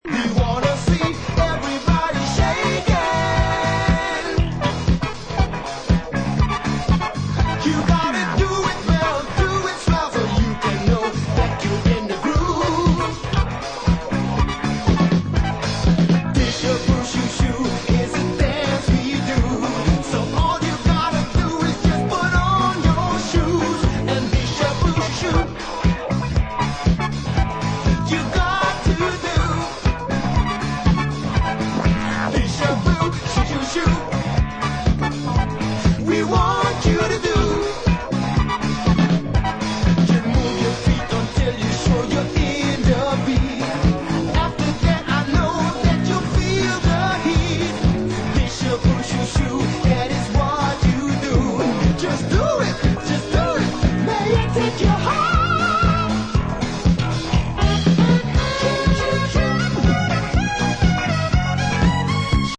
Classical synth-driven disco
This reissue has got a still fresh space-disco-quality to it